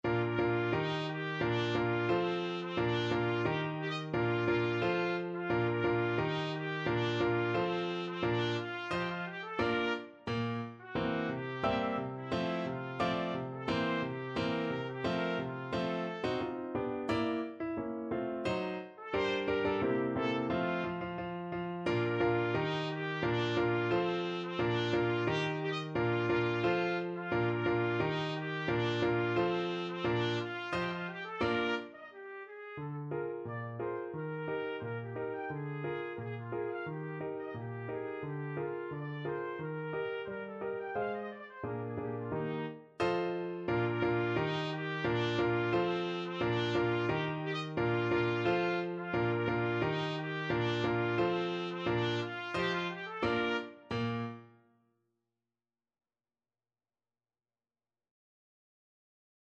Trumpet version
2/4 (View more 2/4 Music)
~ = 88 Stately =c.88
Classical (View more Classical Trumpet Music)